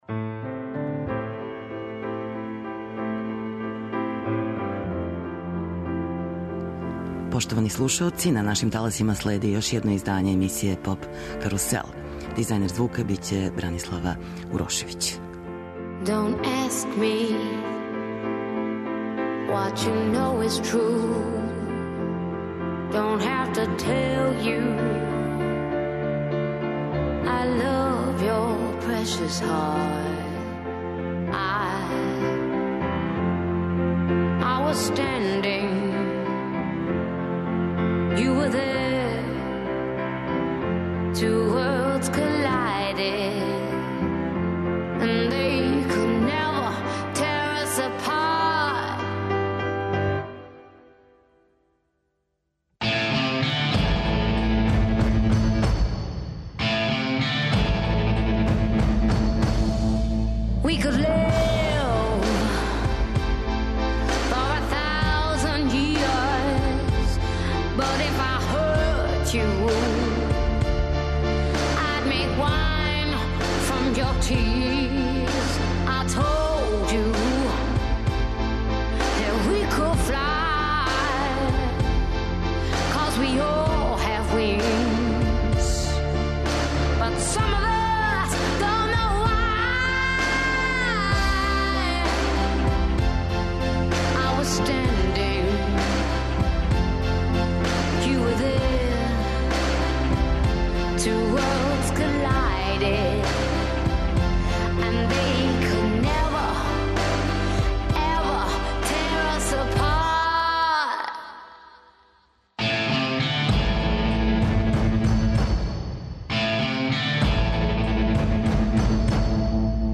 Наша гошћа је Нина Краљић, певачица која ће представљати Хрватску на такмичењу за Песму Евровизије у мају ове године у Стокхолму.